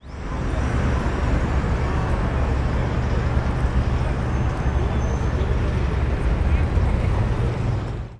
City-Noise-Ambient-4_1.wav